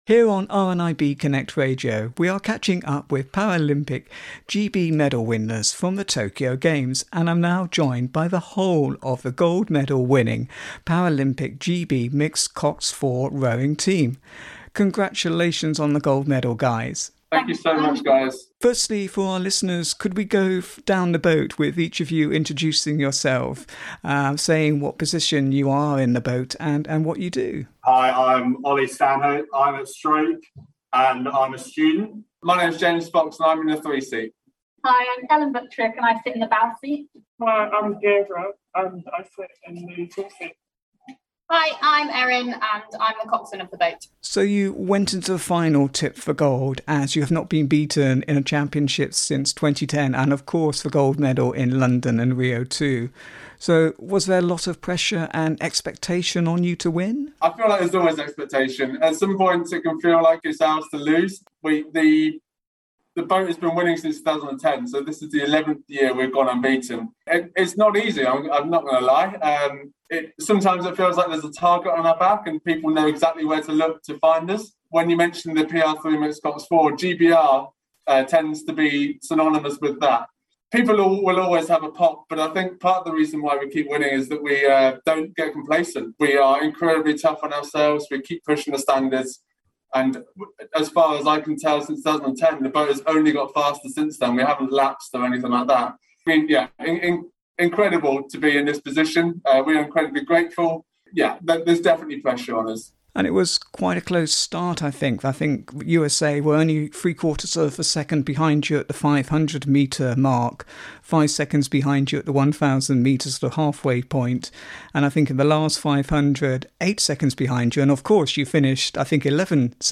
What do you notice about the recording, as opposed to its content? With more medals coming in for the Paralympics GB team out in Tokyo, here on RNIB Connect Radio we are catching up with GB medal winners.